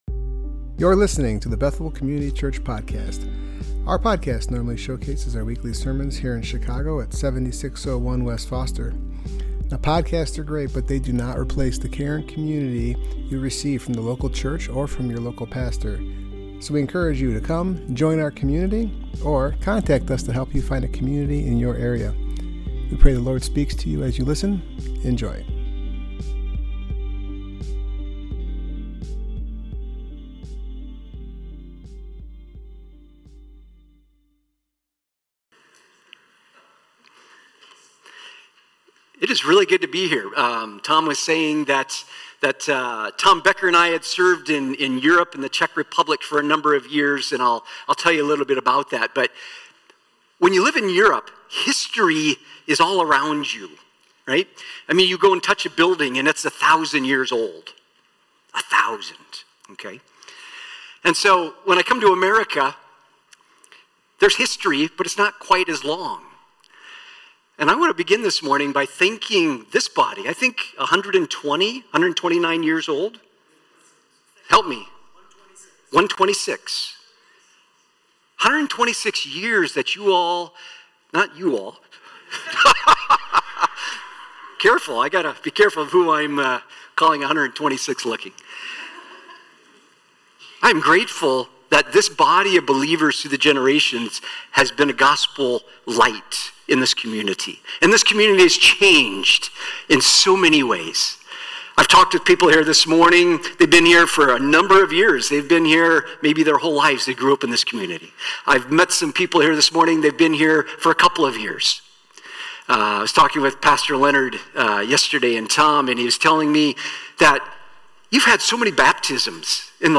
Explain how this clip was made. Passage: John 4:27-42 Service Type: Worship Gathering